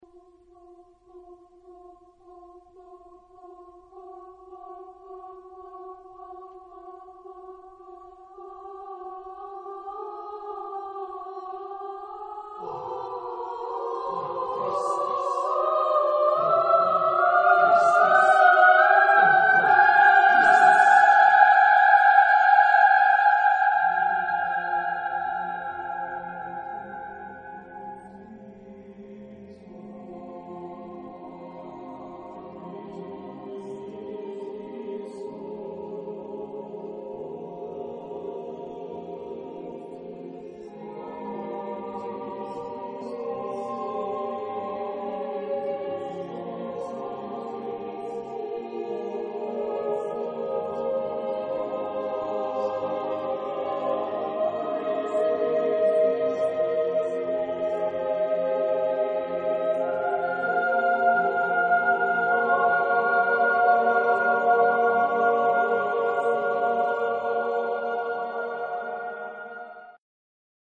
Epoque: 20th century
Genre-Style-Form: Vocal piece ; Sacred
Type of Choir: mixed
Instruments: Timpani ; Bongos